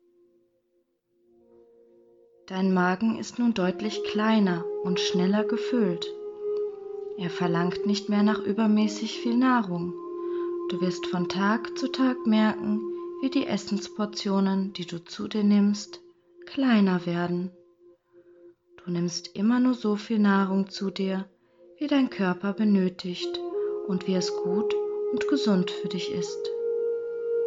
In dieser werden hilfreiche Suggestionen in einer „Schleife“ abgespielt, welche das Verankern im Unterbewusstsein fördert.